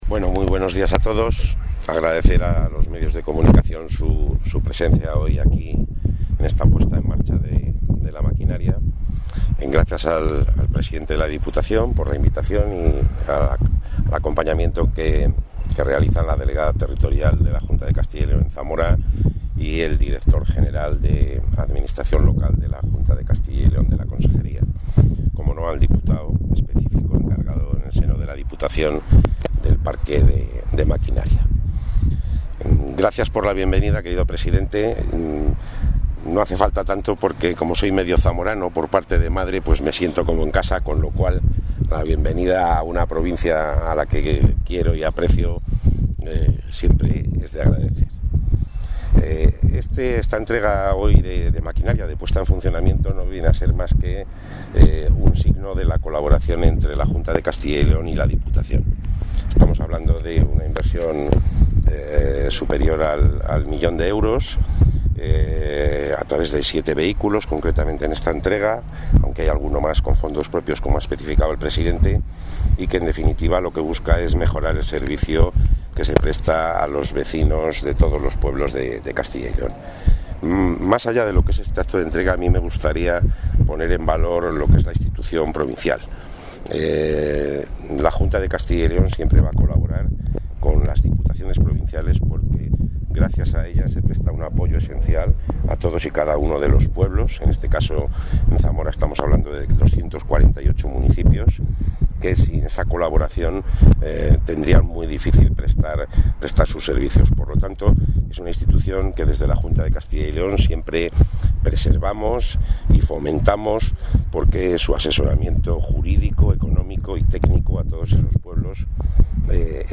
Intervención del consejero.
El consejero de la Presidencia, Luis Miguel González Gago, ha asistido a la presentación de 7 vehículos que la Diputación de Zamora ha adquirido con fondos aportados por la Consejería. En este acto, González Gago ha reivindicado la calidad de vida en el medio rural y ha cuestionado los mensajes fatalistas sobre el futuro de este medio.